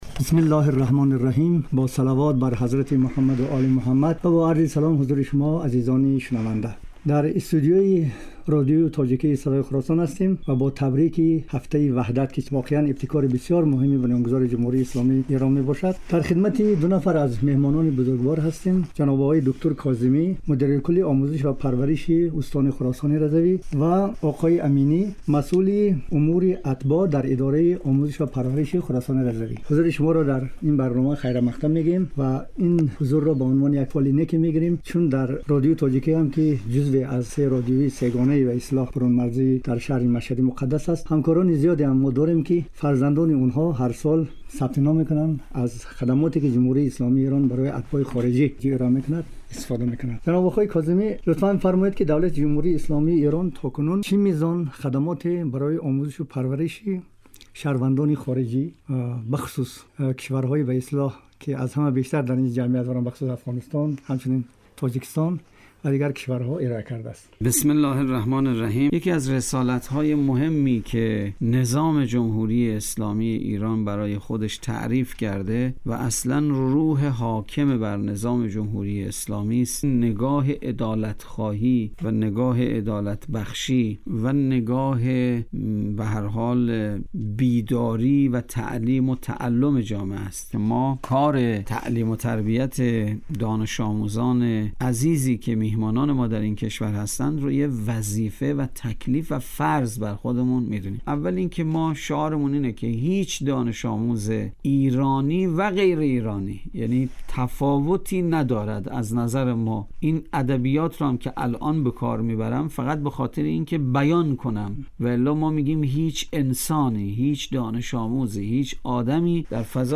گفت و گو با دو مهمان برنامه را می شنویم.